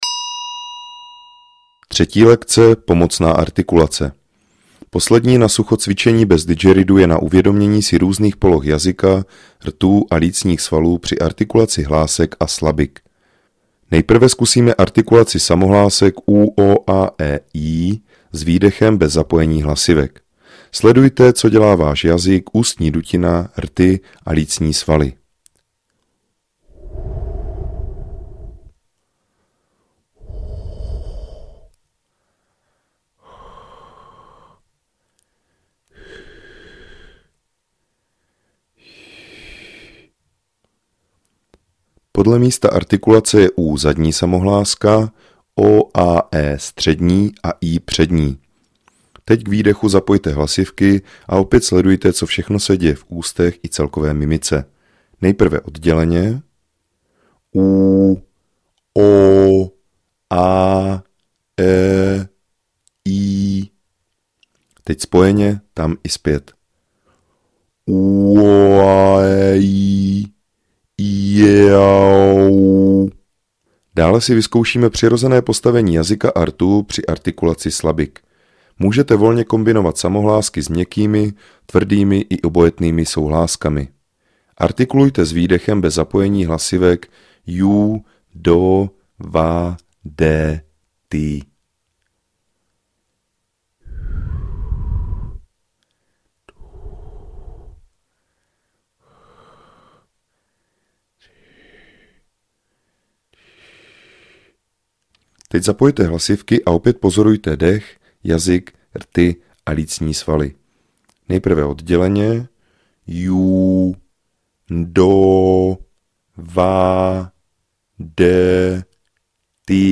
VÝUKA HRY NA DIDGERIDOO I.
Didgeridoo je nástroj, jehož hluboký tón dokáže ukotvit pozornost v přítomném okamžiku.
Track 11 - 3 lekce - Pomocna artikulace.mp3